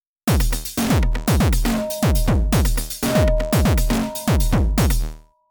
just for the record, those were my first three tries on day one of owning a Machinedrum …